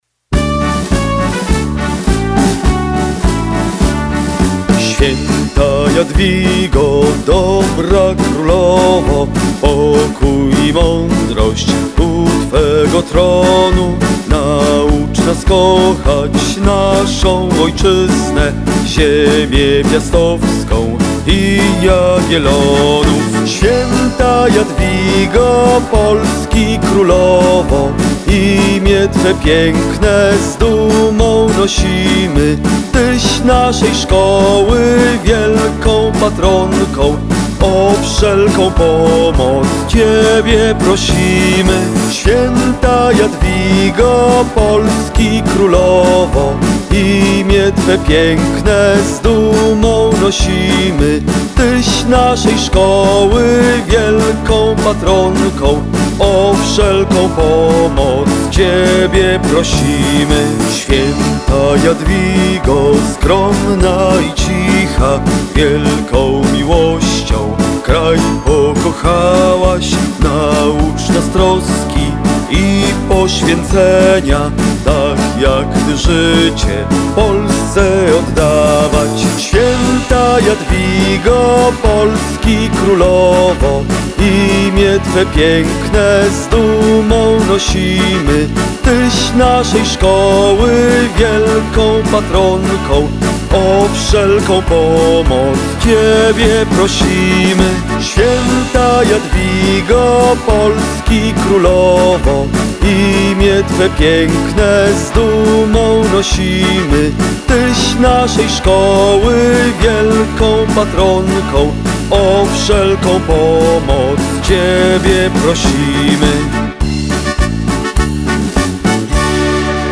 Święta Jadwigo - hymn szkoły